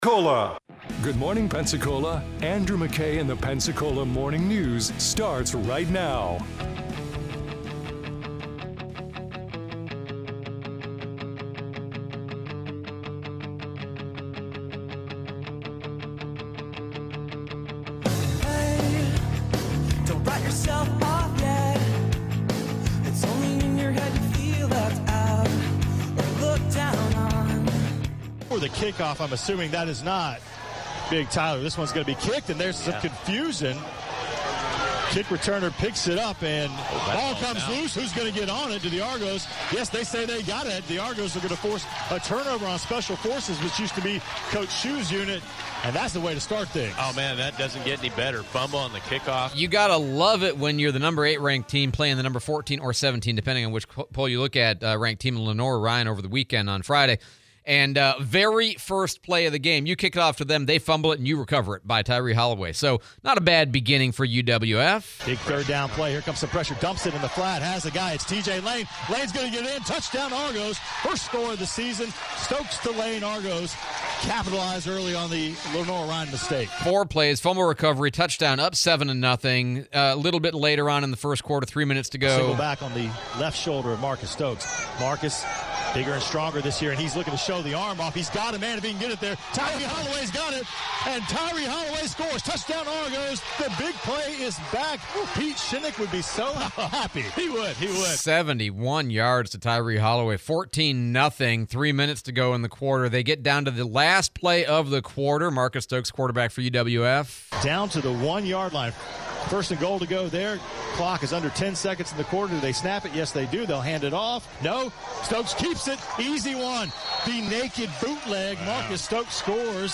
Argos Football, changing habits, interview with Congressman Patronis